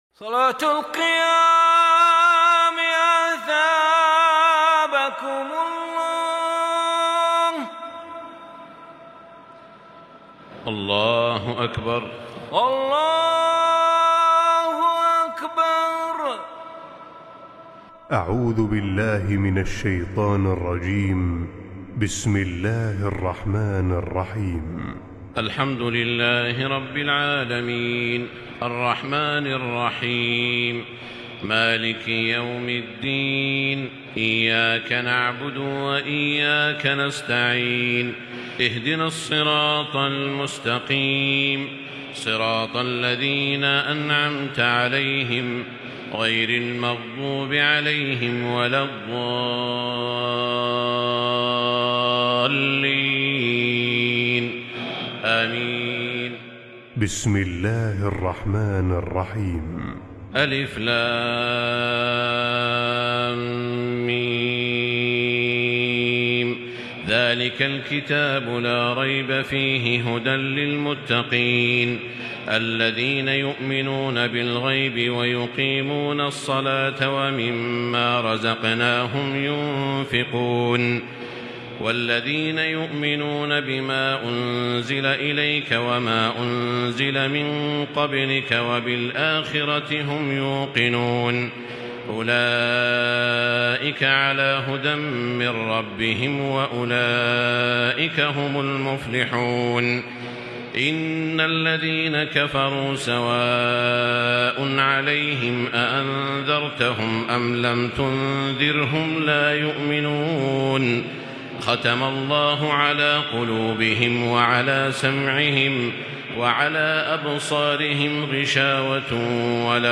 تراويح الليلة الأولى رمضان 1440هـ من سورة البقرة (1-82) Taraweeh 1st night Ramadan 1440H from Surah Al-Baqara > تراويح الحرم المكي عام 1440 🕋 > التراويح - تلاوات الحرمين